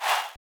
cleanchant.wav